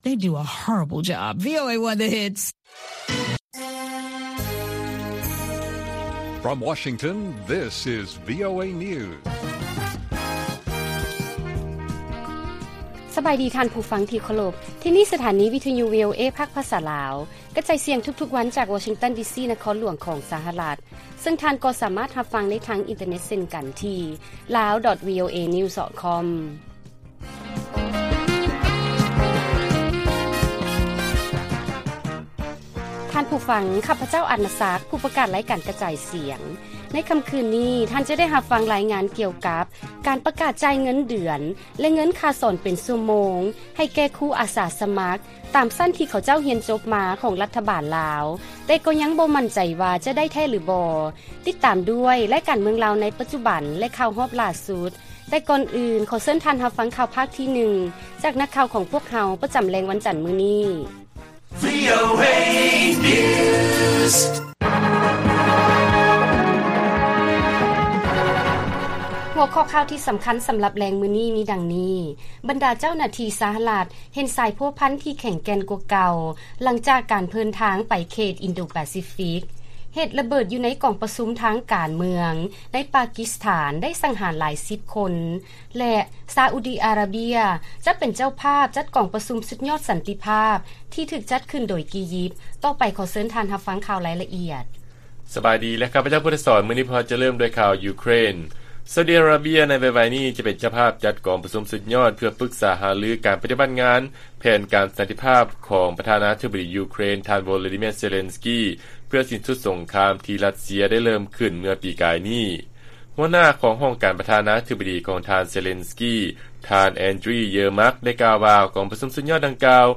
ລາຍການກະຈາຍສຽງຂອງວີໂອເອ ລາວ: ຊາອຸດີ ອາຣາເບຍ ຈະເປັນເຈົ້າພາບກອງປະຊຸມສຸດຍອດສັນຕິພາບ ທີ່ຖືກຈັດຂຶ້ນໂດຍ ກີຢິບ